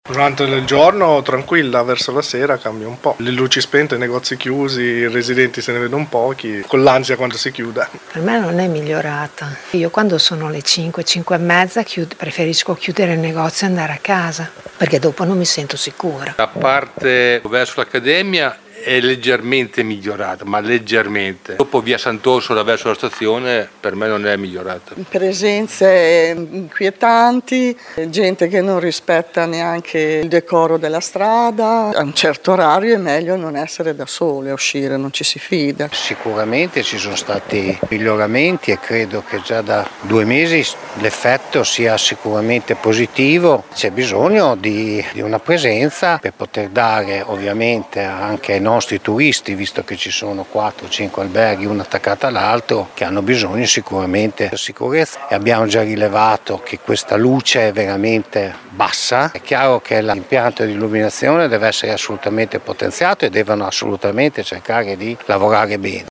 Ma cosa ne pensa chi risiede o lavora nella zona?
VOX-ZONA-TEMPIO.mp3